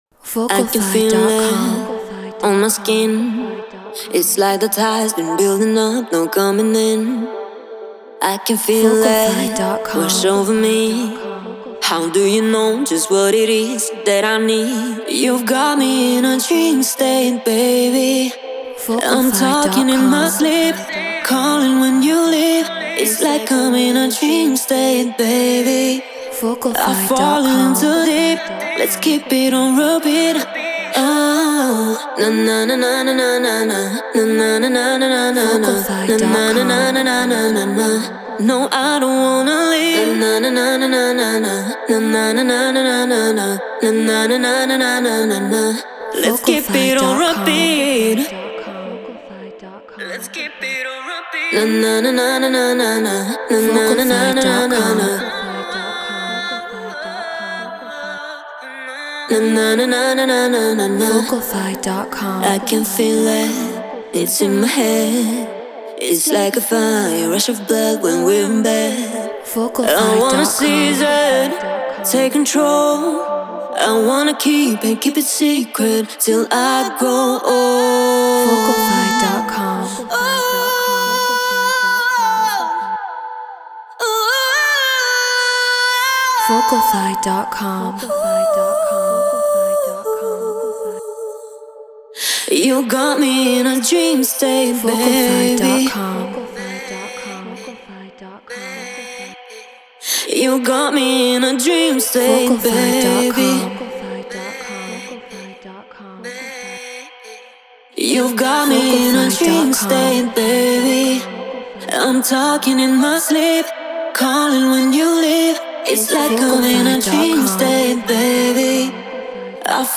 Deep House 124 BPM Amin
Neumann TLM 103 UAD Apollo Twin Ableton Live Treated Room